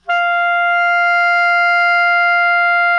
SOP  MF F 4.wav